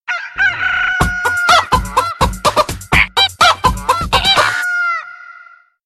• Качество: 192, Stereo
без слов